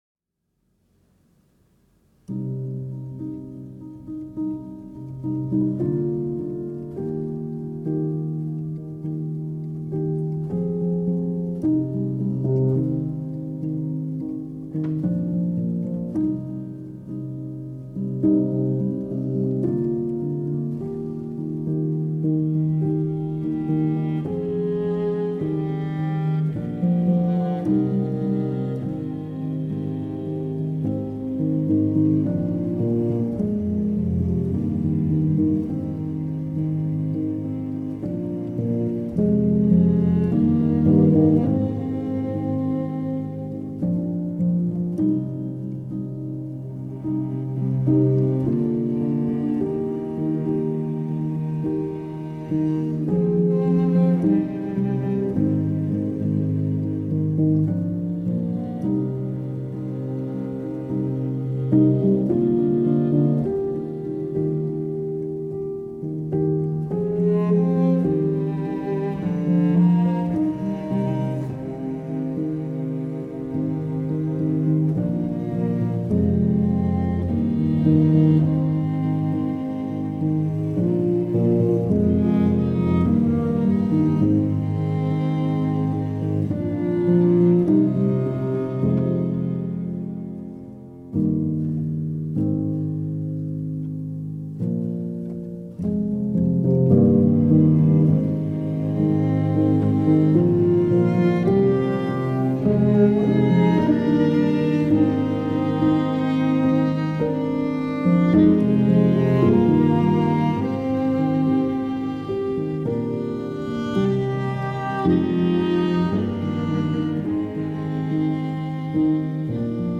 rework